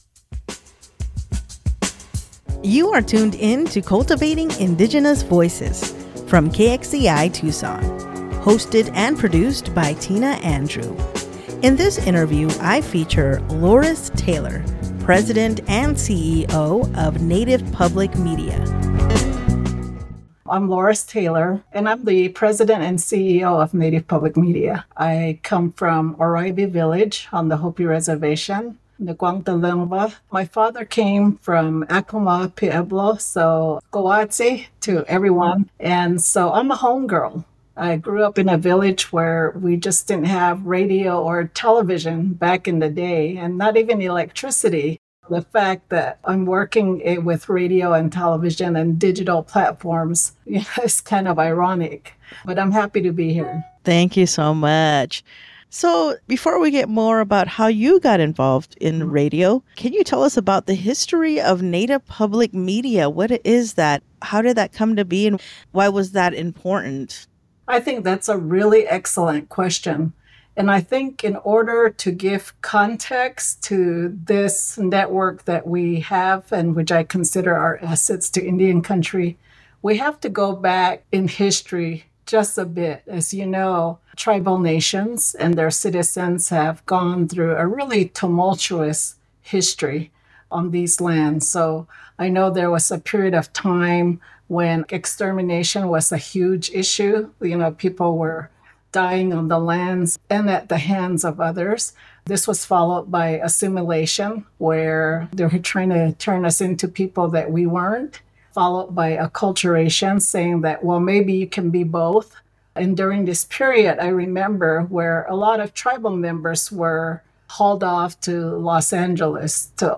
Here is a brief version of the interview that offers key highlights and insights from the full conversation.